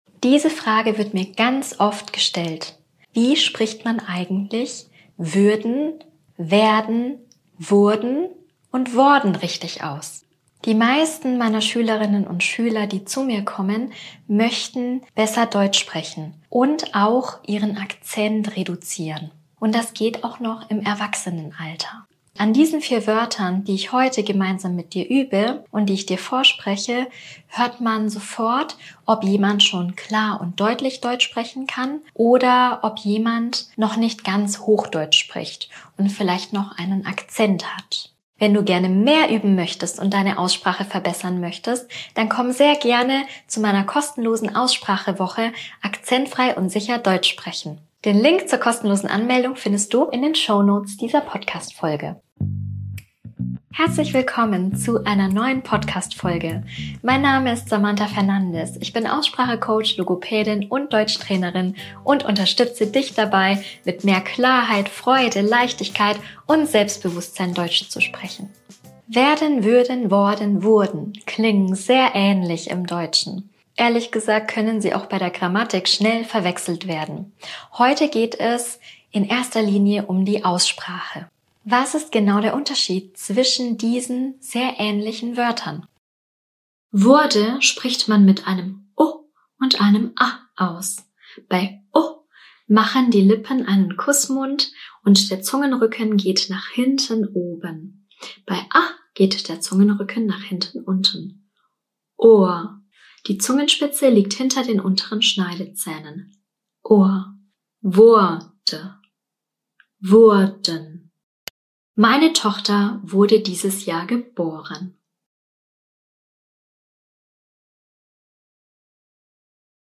spreche die Wörter einzeln und in Beispielsätzen für deinen Alltag
vor, du kannst einfach zuhören und in den Pausen nachsprechen.